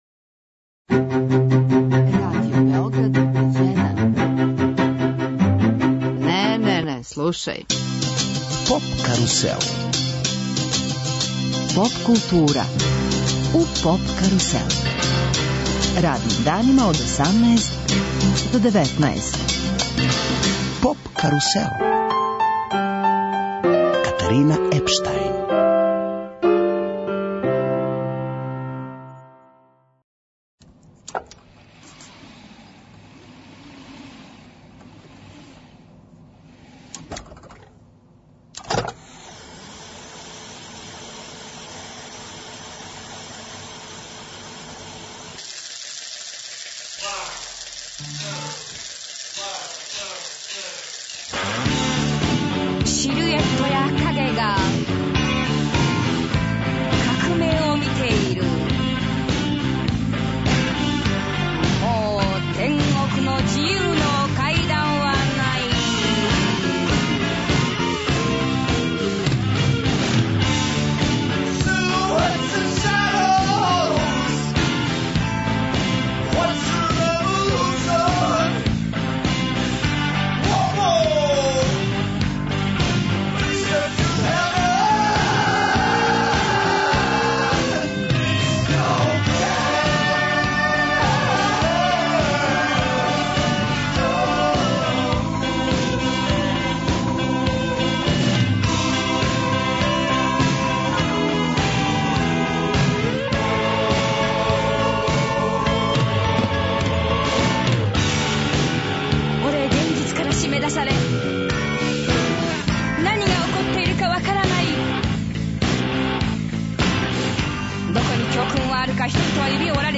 Разговором са њим "отварамо" серијал емисија посвећених Дејвиду Боувију.